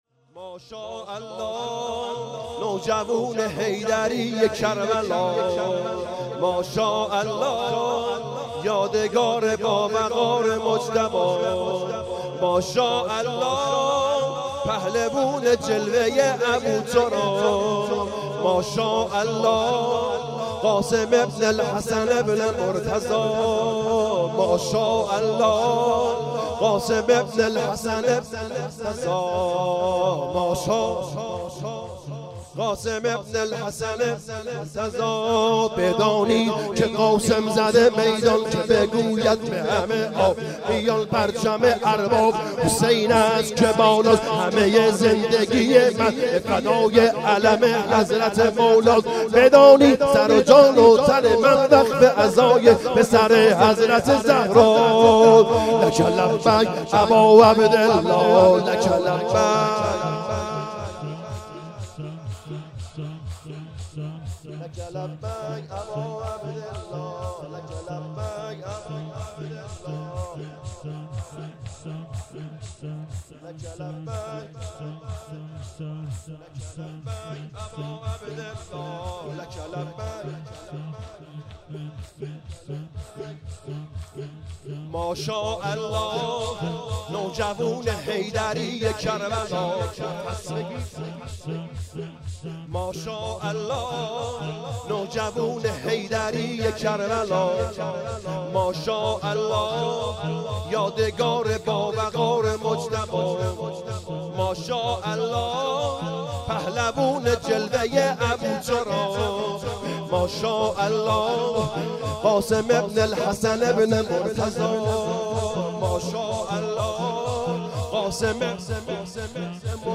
شب ششم محرم